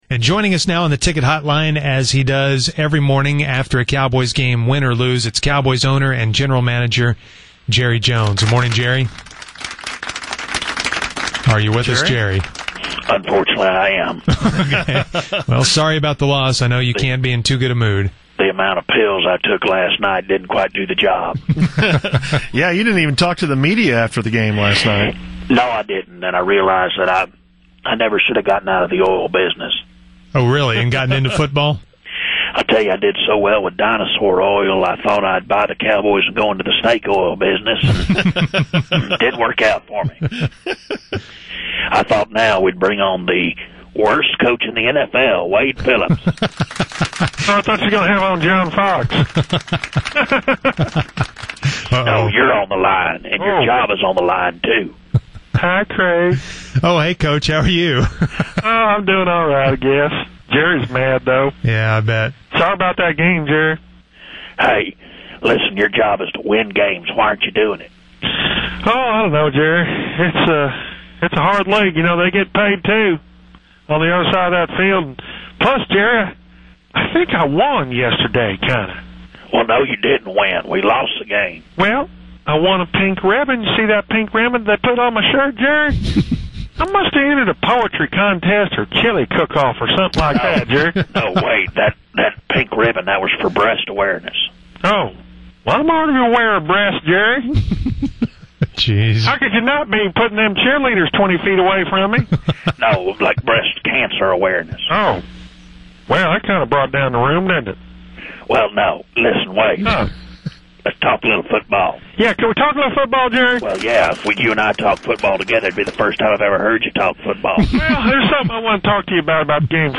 Fake Jerry and Fake Wade Talk Cowboys Loss...